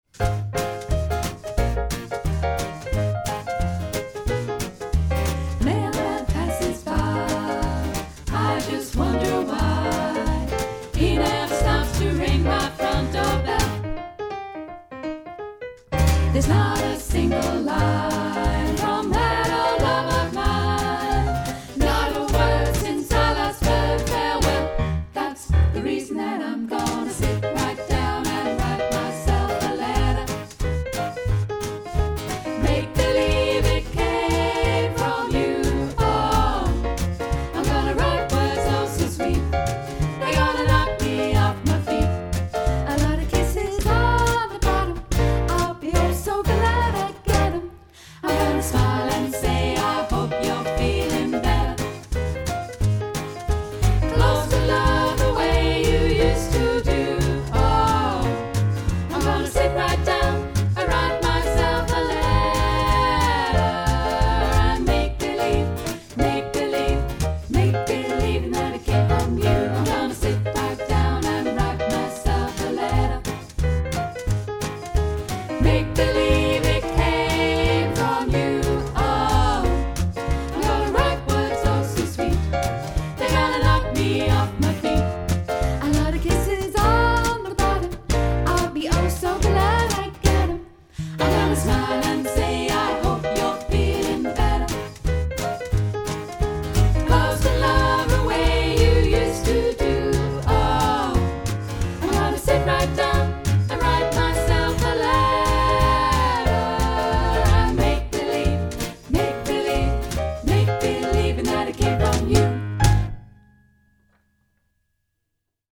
Swing stuff